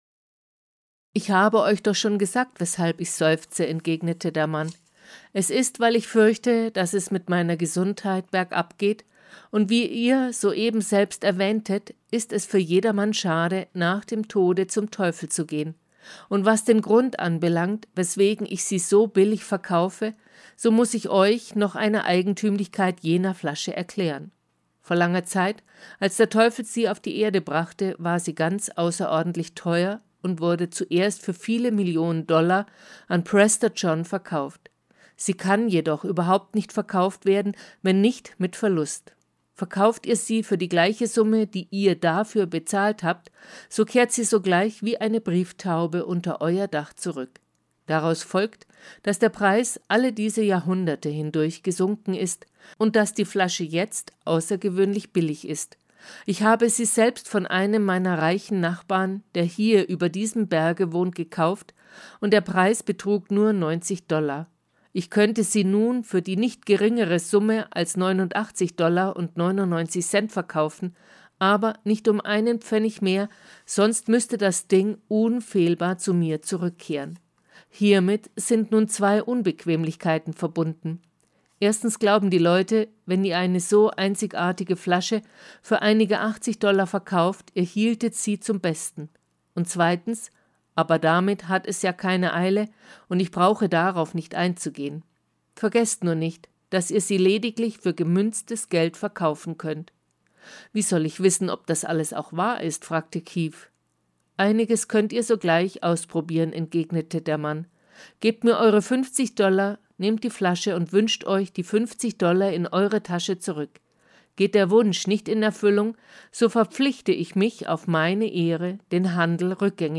DAISY-Hörbuch